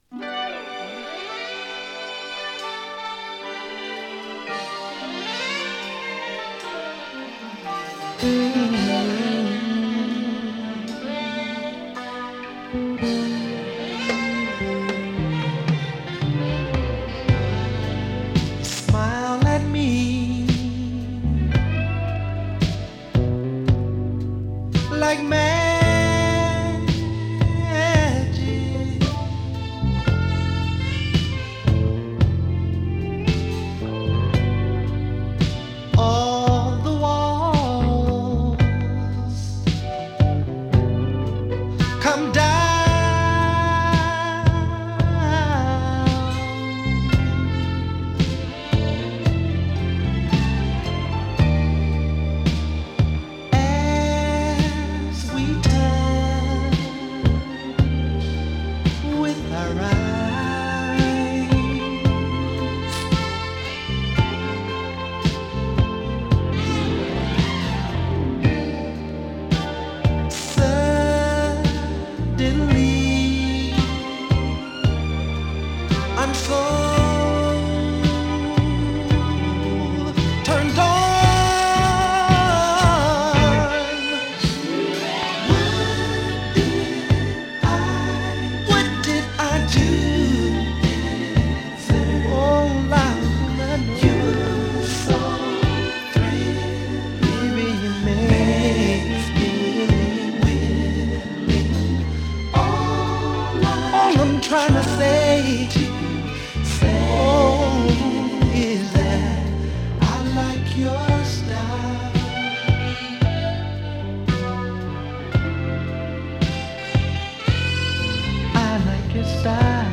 キャッチー ディスコ ファンク
ホーンのイントロとファルセット・ボーカルが印象的なディスコ・ファンク！